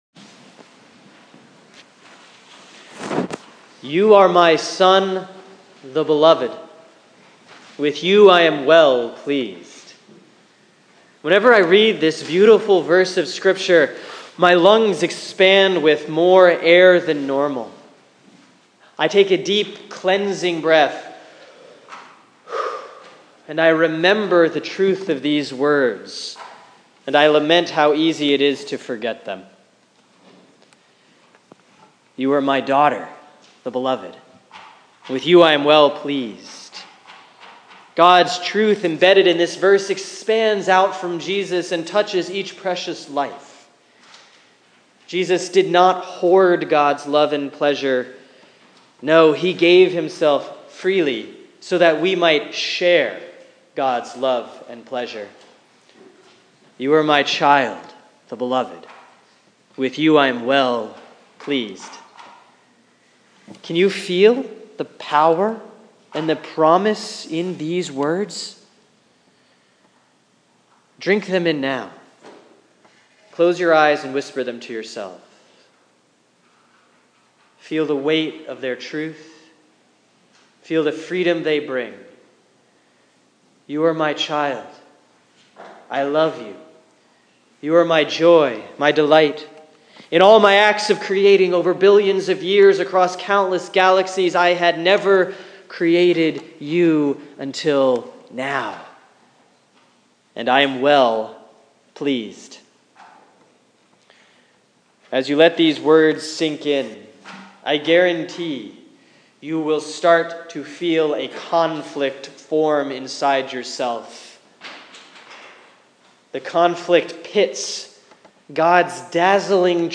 Sermon for Sunday, January 10, 2016 || Epiphany 1C || Luke 3:15-17, 21-22